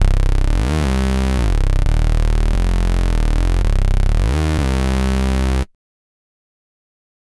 synth01.wav